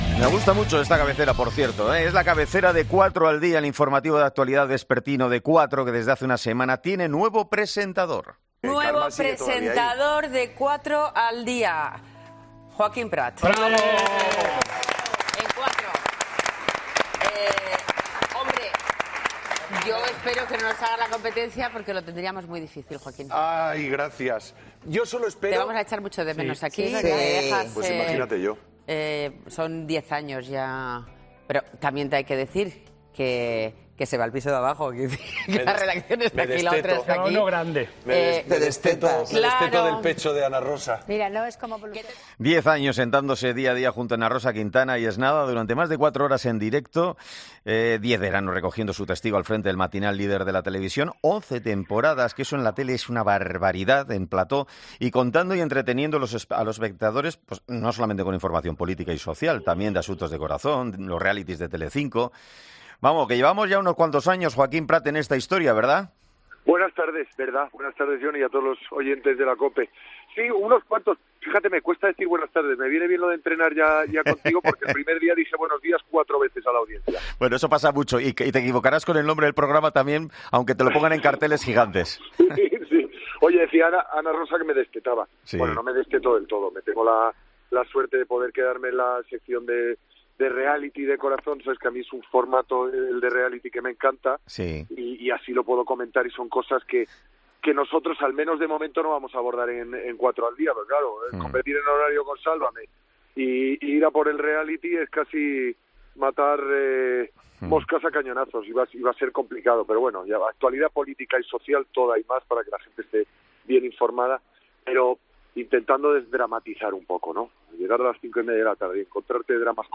Según ha dicho este lunes en 'Herrera en COPE', aunque no se "desteta" del todo de Ana Rosa Quintana, con la que ha compartido plató durante diez años y con la que seguirá analizando la crónica rosa, afronta con ganas este reto y con el propósito de analizar la "actualidad política y social" intentando "desdramatizarla un poco".